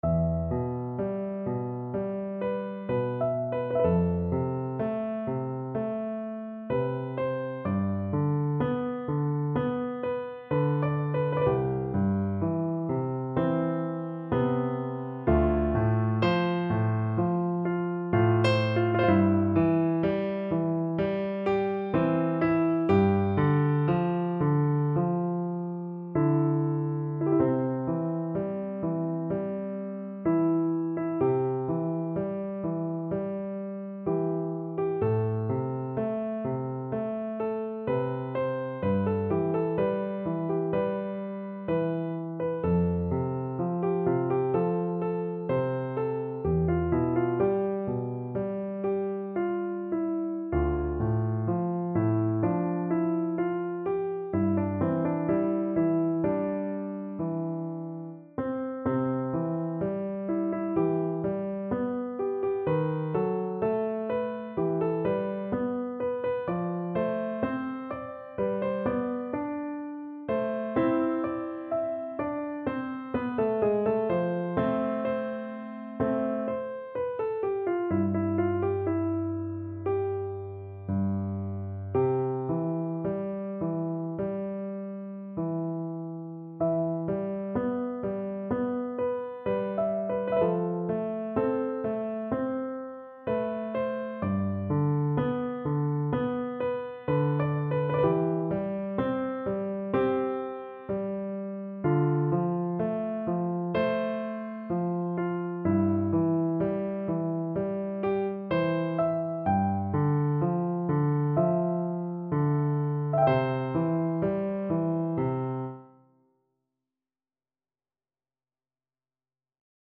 Classical Salvatore Cardillo Core 'Ngrato (Catari, Catari) Piano version
C major (Sounding Pitch) (View more C major Music for Piano )
4/4 (View more 4/4 Music)
Andante moderato con sentimento = c. 63 (View more music marked Andante Moderato)
Piano  (View more Intermediate Piano Music)
Classical (View more Classical Piano Music)
core_ngrato_PNO.mp3